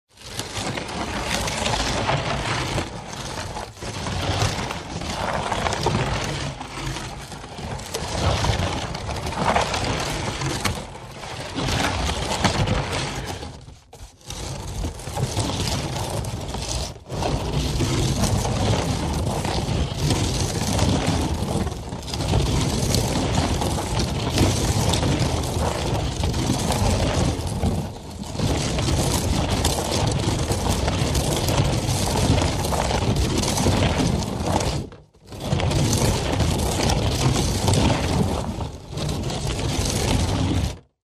Катапульта едет по проселочной дороге